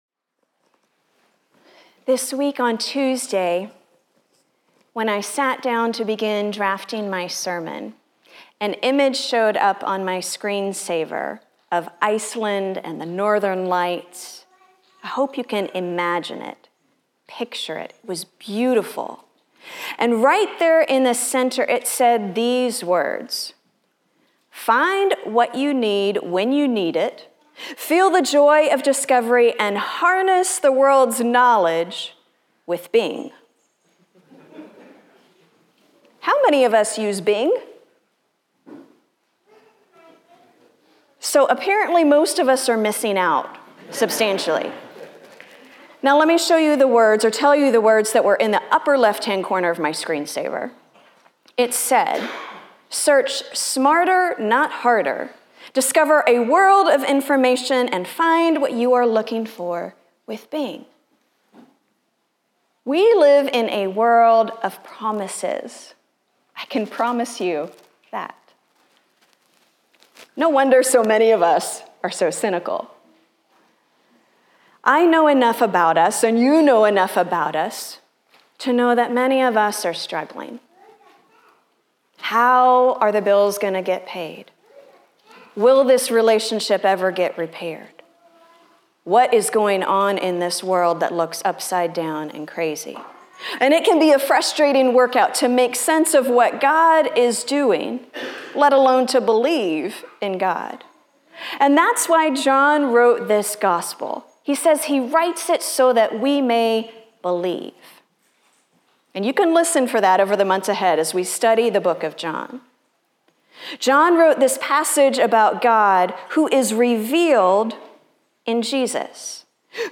- Isaiah 55:1-13 Order of worship/bulletin Youtube video recording Sermon audio recording.